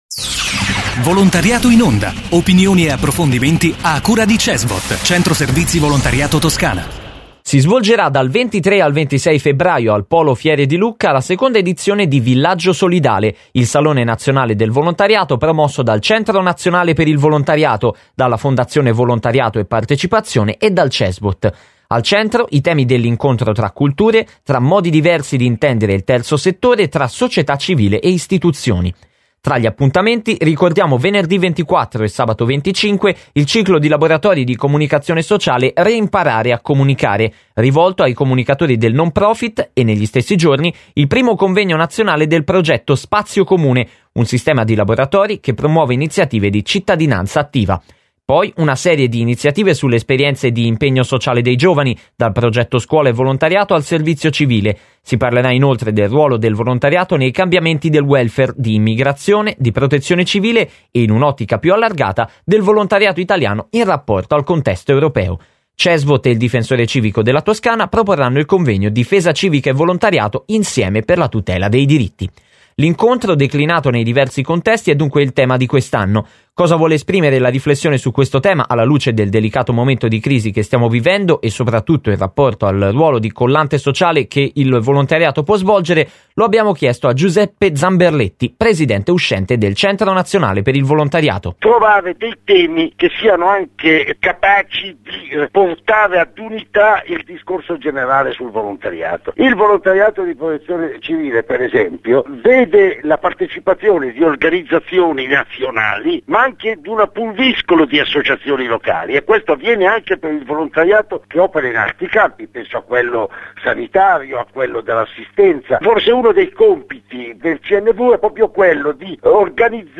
Intervista a Giuseppe Zamberletti, presidente uscente del Cnv.